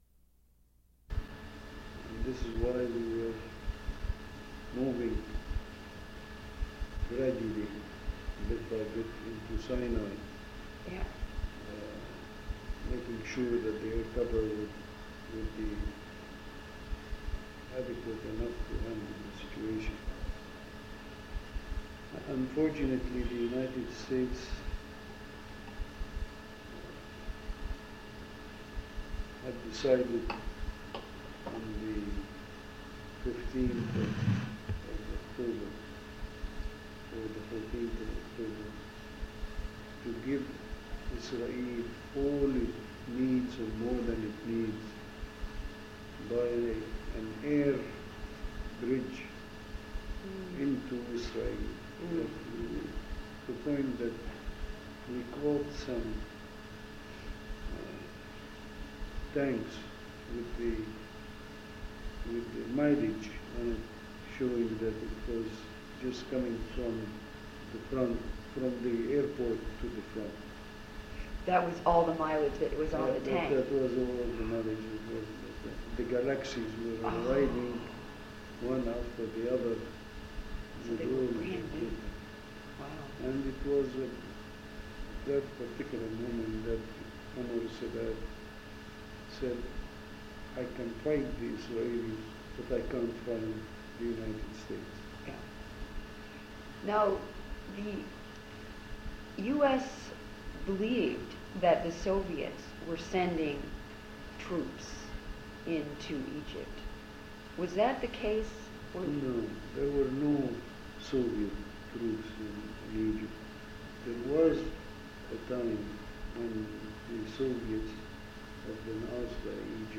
Symbole *** Titre Interview with Ashraf Ghorbal